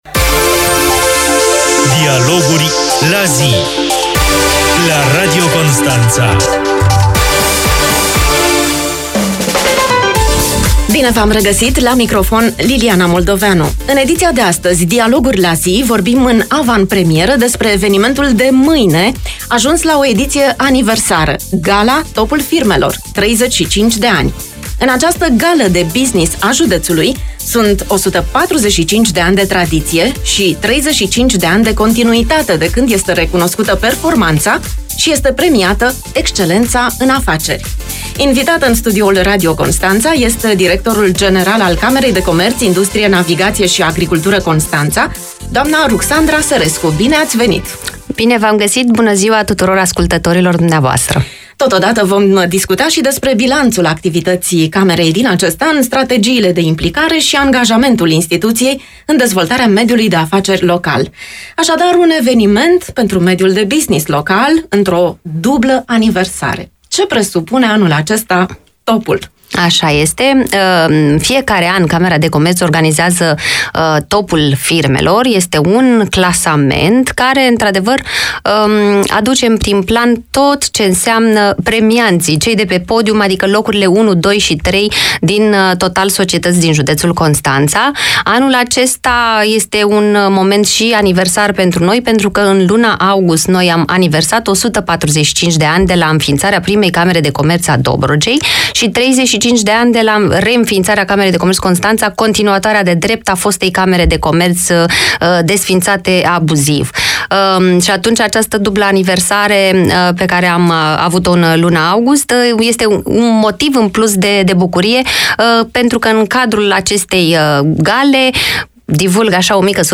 în studioul Radio Constanța